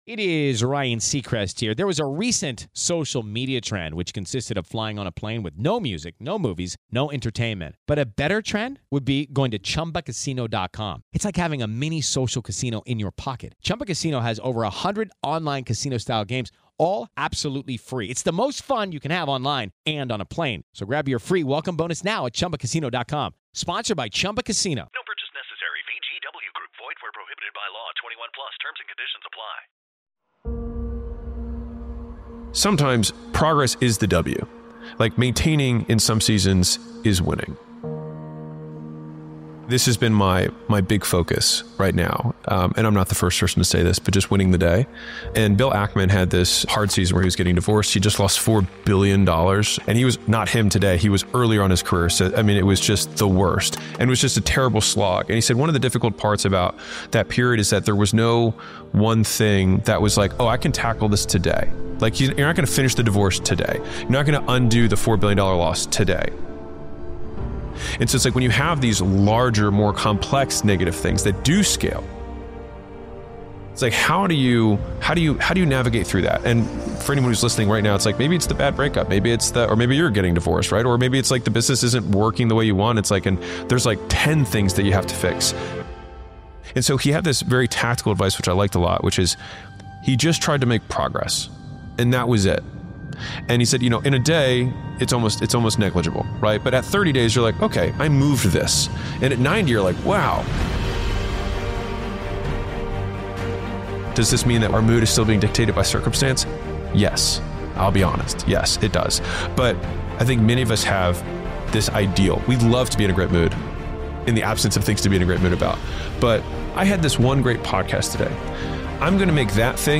Brutally honest advice from the man who makes millionaires. One of the Best Motivational Speeches featuring Alex Hormozi.